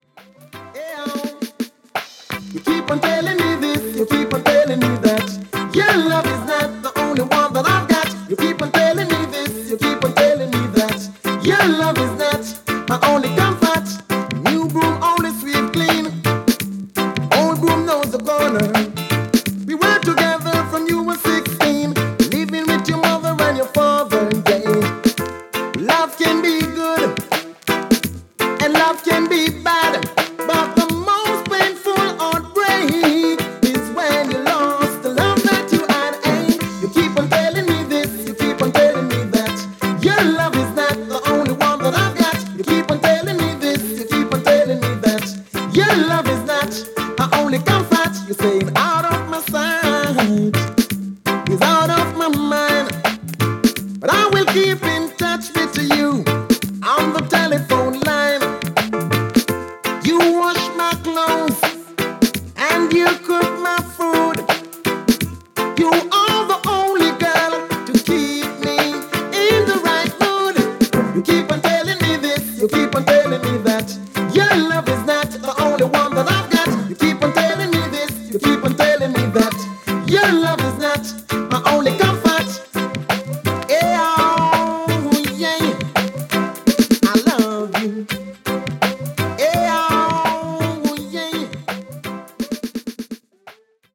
ジャマイカン・シンガー